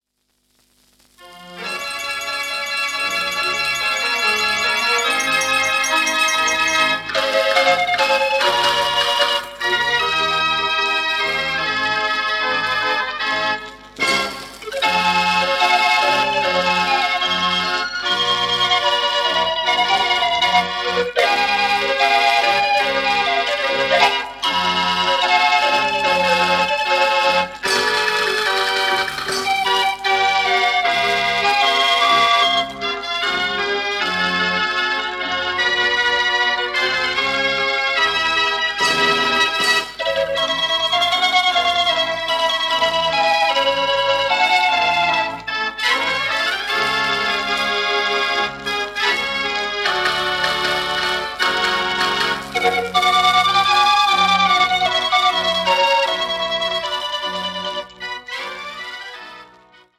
Formaat Grammofoonplaat, vermoedelijk 78 toeren
Tango